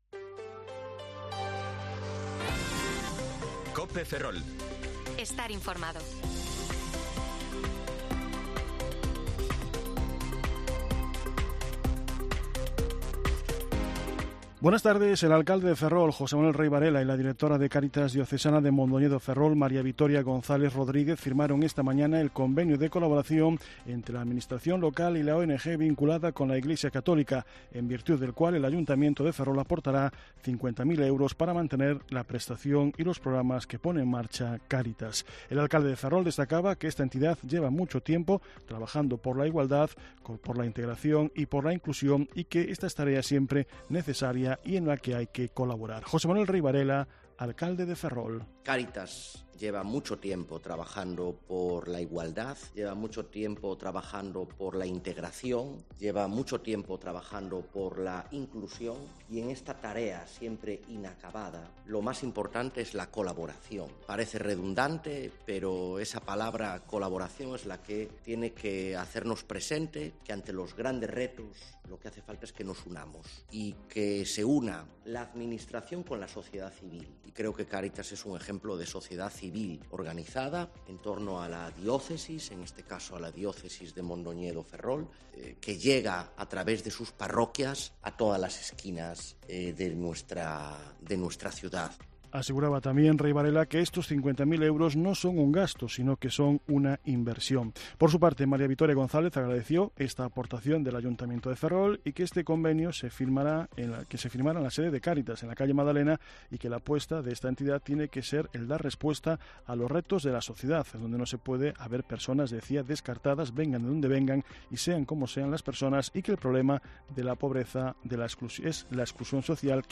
Informativo Mediodía COPE Ferrol 7/9/2023 (De 14,20 a 14,30 horas)